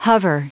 Prononciation du mot hover en anglais (fichier audio)
Prononciation du mot : hover